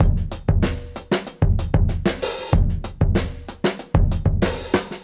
Index of /musicradar/dusty-funk-samples/Beats/95bpm
DF_BeatA_95-01.wav